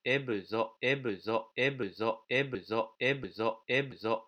「ebuzo」の音声から徐々に「u」の音を削っていくと、どのように聞こえるだろう？ 日本人は母音を補って聞く傾向があるため、変化に気づきにくい。